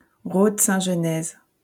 ;[a] French: Rhode-Saint-Genèse [ʁɔd sɛ̃ ʒənɛz]